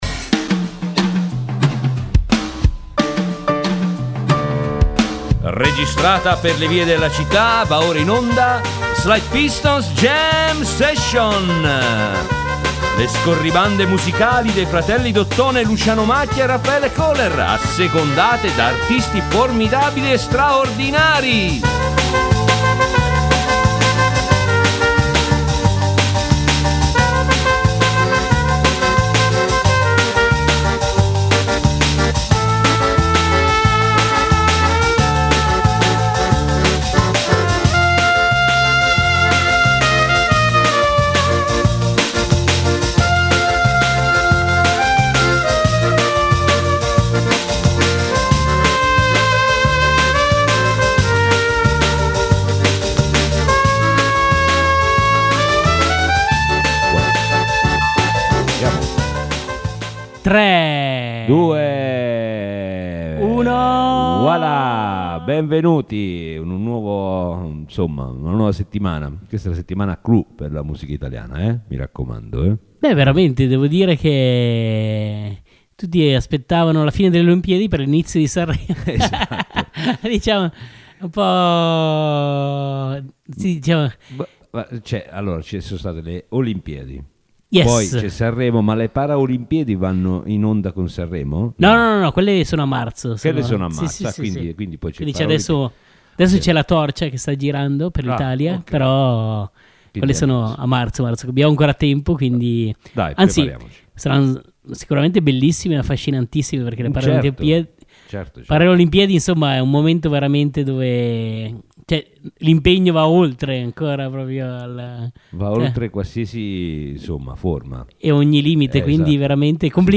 In onda le scorribande musicali dei due suonatori d’ottone in giro per la città, assecondate da artisti formidabili e straordinari.